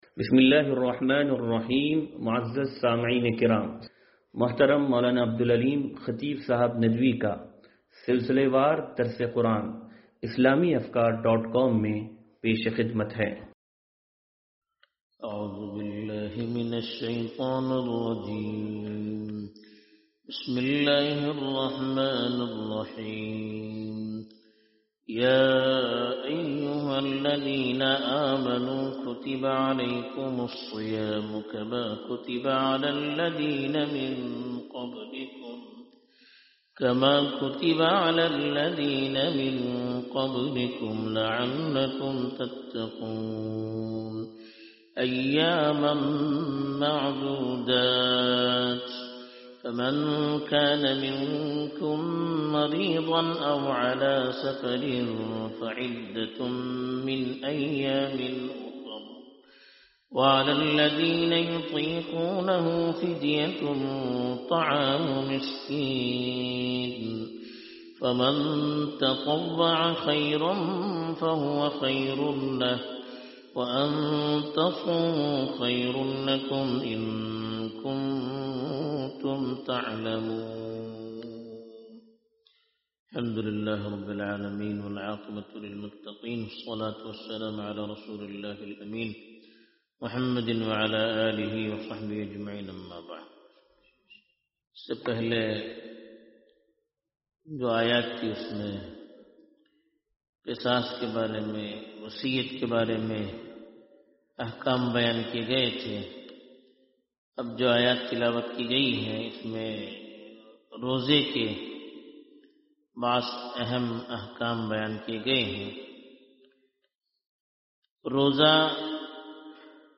درس قرآن نمبر 0131